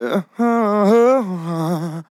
Categories: Vocals Tags: AH, DISCO VIBES, dry, english, fill, HEAHH, male, sample
man-disco-vocal-fills-120BPM-Fm-7.wav